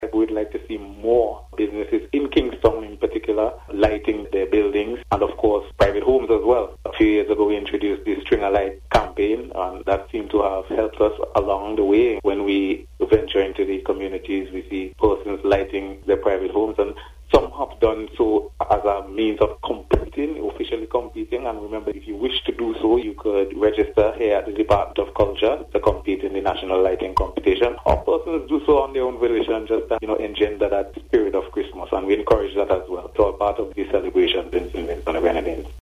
during an interview with NBC News.